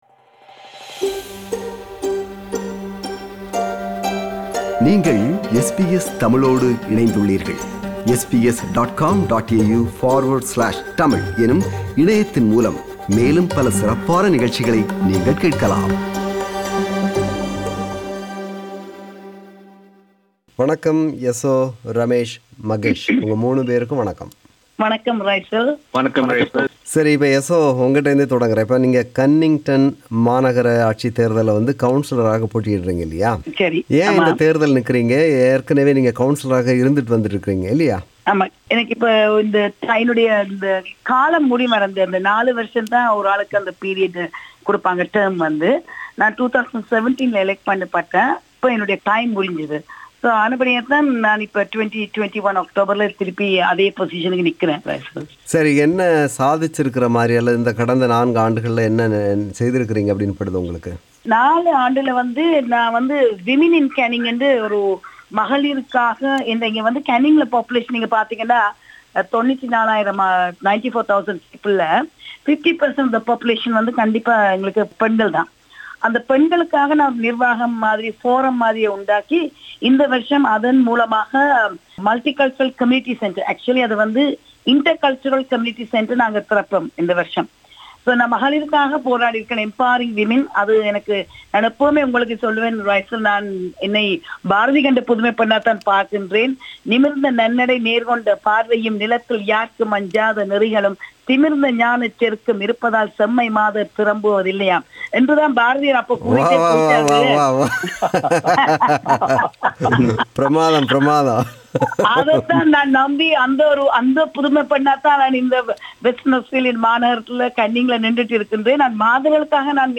A discussion with Tamil candidates in WA local government election